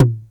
Smooth Tom Drum One Shot A# Key 38.wav
Royality free tom sample tuned to the A# note. Loudest frequency: 372Hz
smooth-tom-drum-one-shot-a-sharp-key-38-WLi.mp3